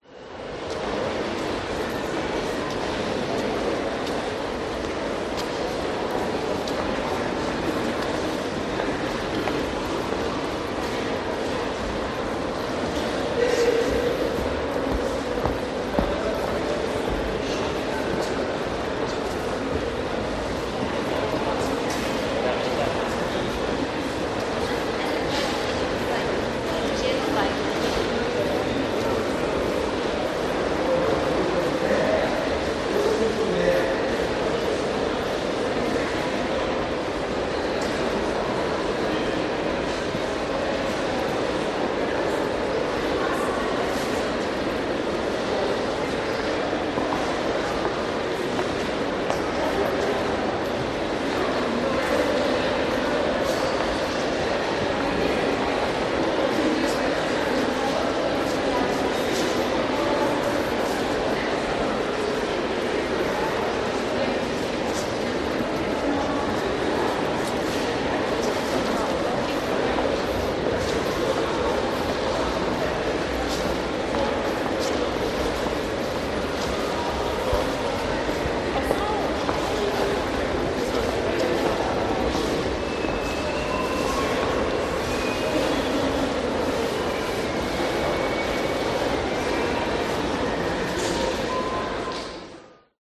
Лувр в Париже: гул голосов в большом зале музея Франции